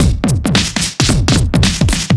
OTG_DuoSwingMixB_110a.wav